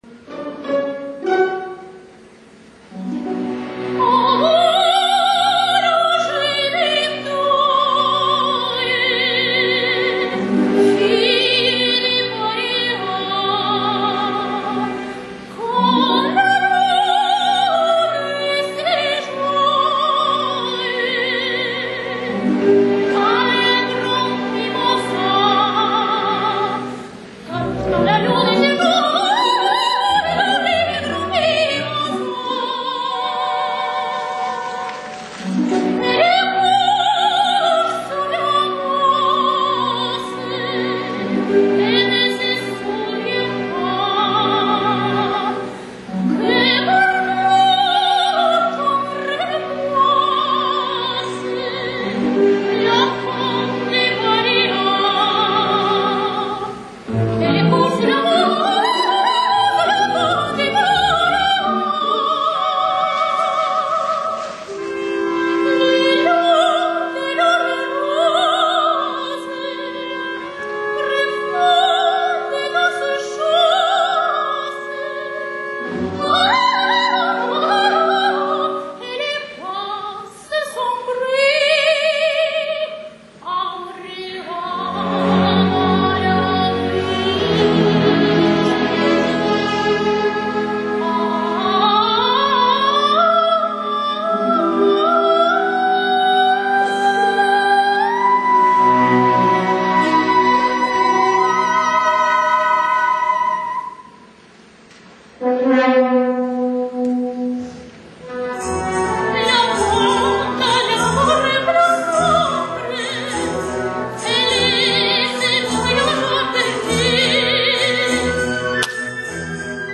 Архив концертных выступлений
г.Волгоград. Волгоградский Академический симфонический оркестр.